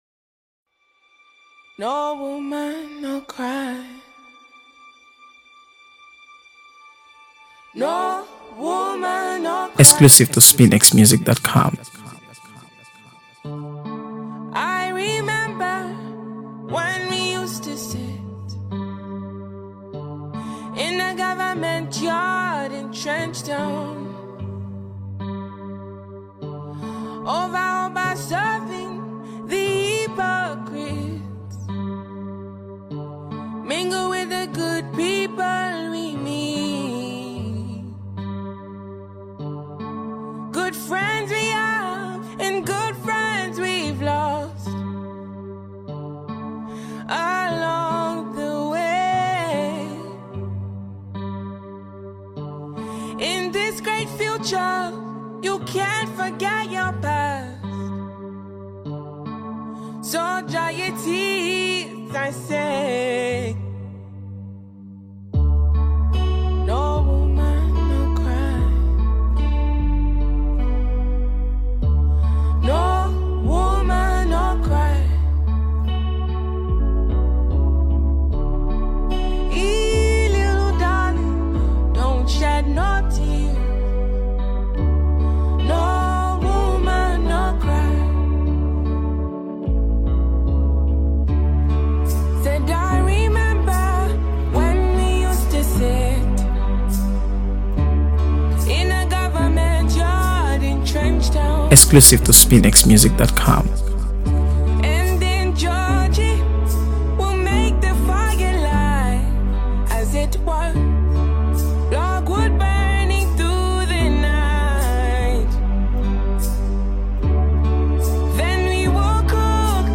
AfroBeats | AfroBeats songs
Nigerian singer